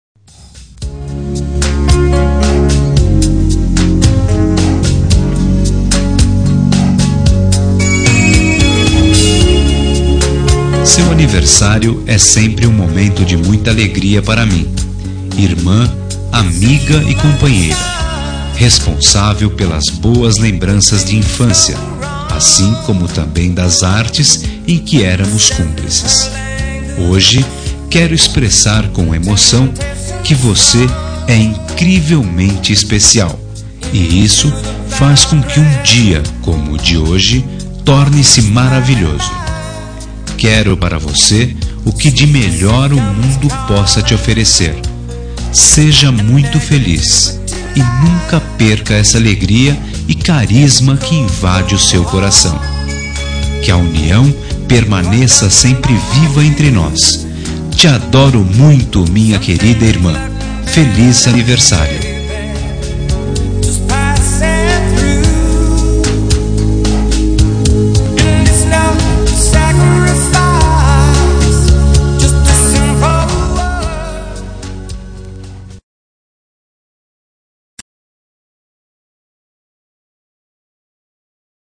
Telemensagem de Aniversário de Irmã – Voz Masculina – Cód: 1677